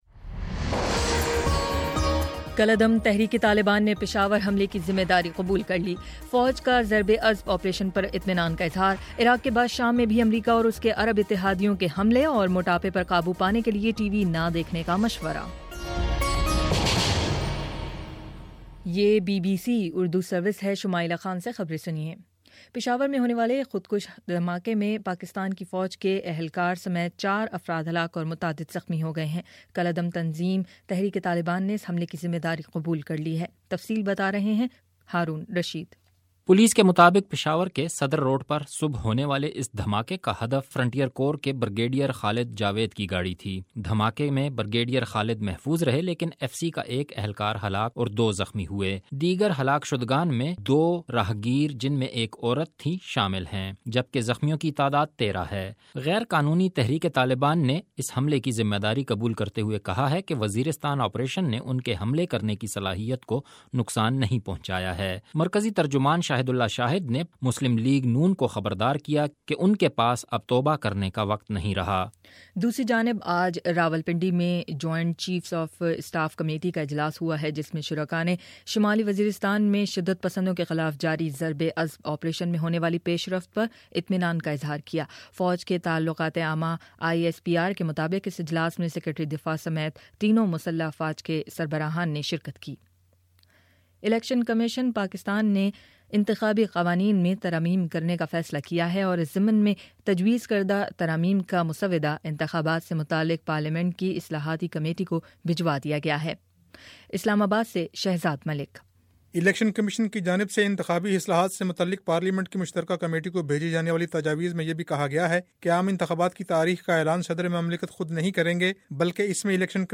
ستمبر 23 : شام چھ بجے کا نیوز بُلیٹن